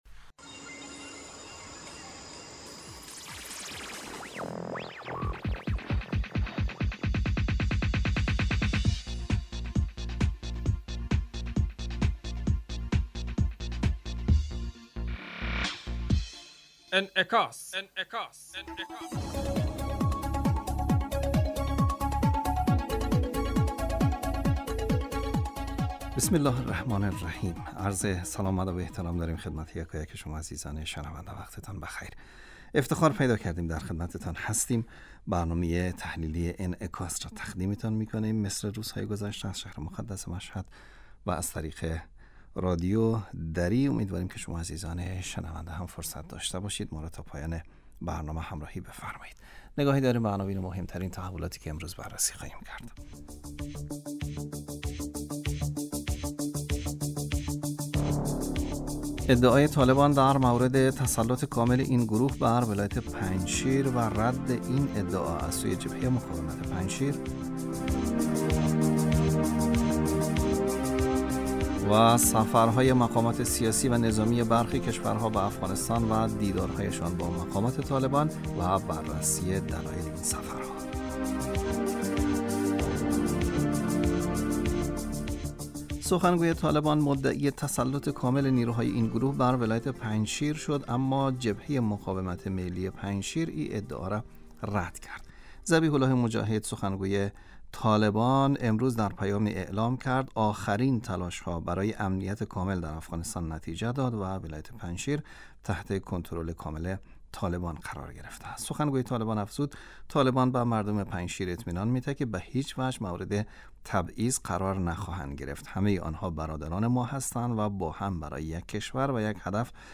ادعای طالبان در مورد تسلط کامل این گروه بر ولایت پنجشیر و رد این ادعا از سوی جبهه مقاومت پنجشیر. سفرهای مقامات سیاسی و نظامی برخی کشورها به افغانستان و دیدارهایشان با مقامات طالبان و بررسی دلایل این سفرها. برنامه انعکاس به مدت 30 دقیقه هر روز در ساعت 12:10 ظهر (به وقت افغانستان) بصورت زنده پخش می شود.